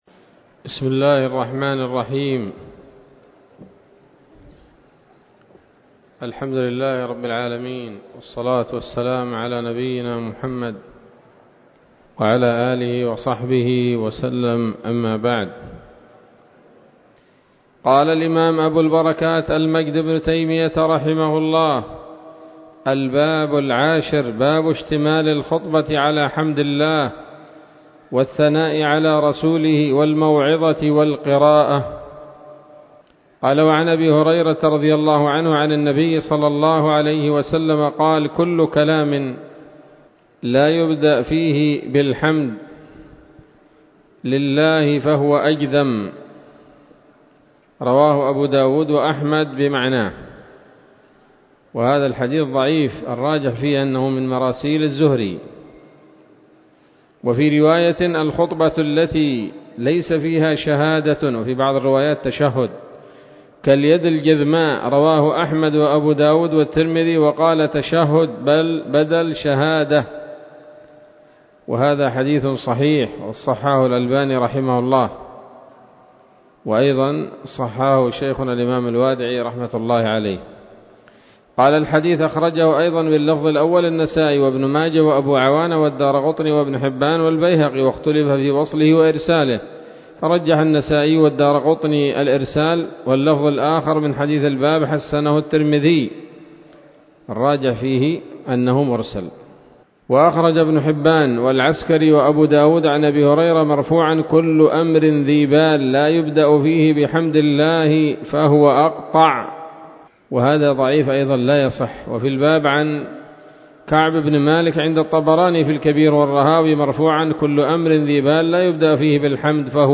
الدرس السادس والعشرون من ‌‌‌‌أَبْوَاب الجمعة من نيل الأوطار